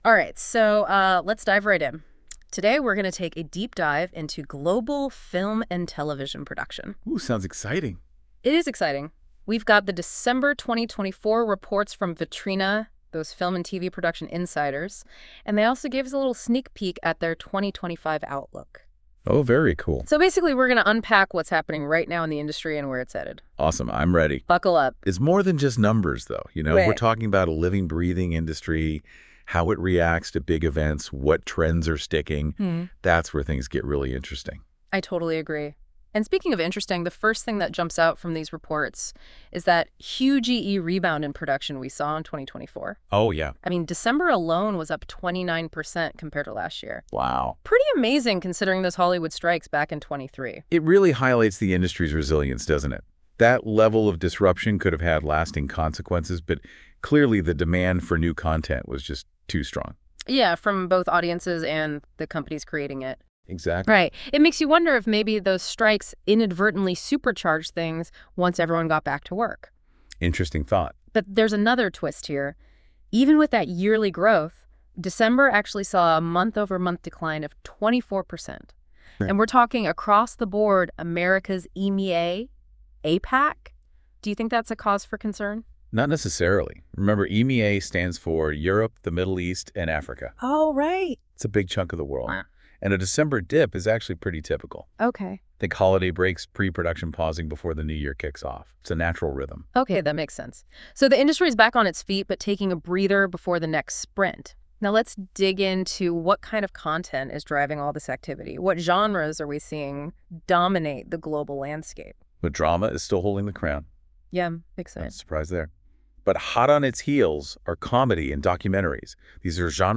The audio podcast was generated with Deep Dive and reviewed by our team.